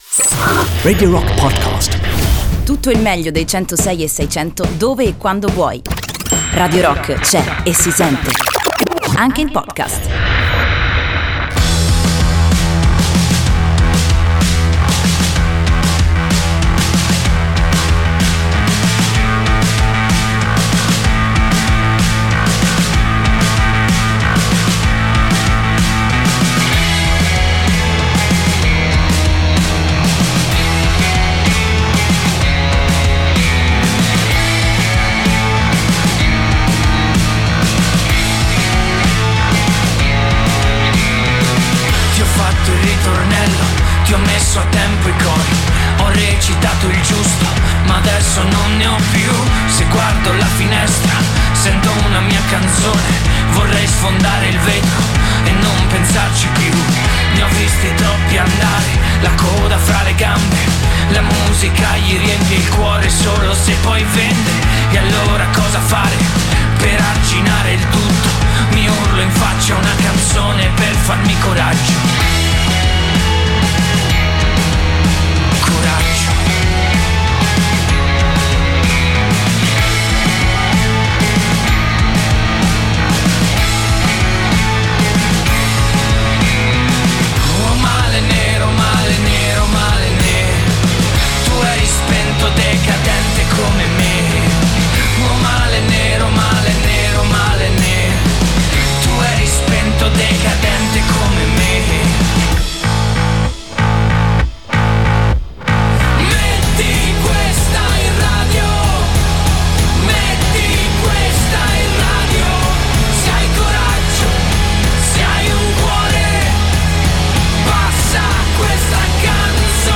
Fast Animals and Slow Kids in diretta negli studi di Radio Rock
Intervista